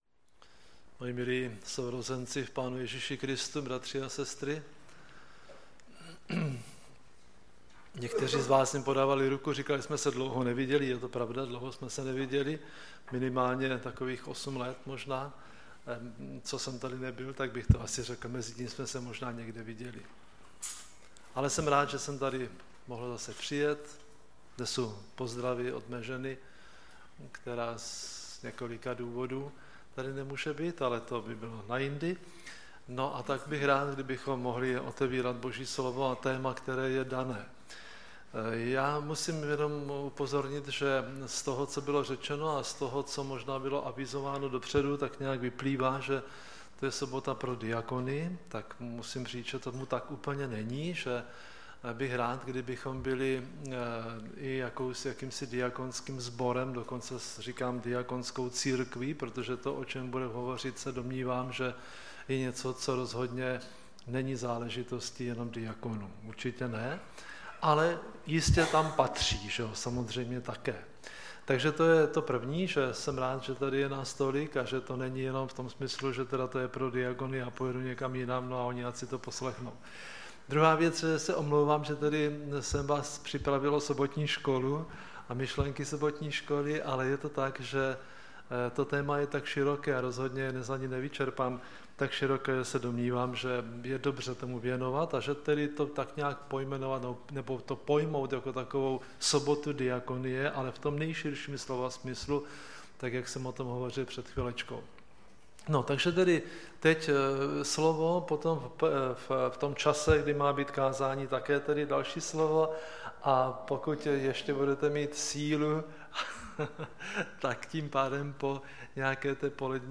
Typ Služby: Přednáška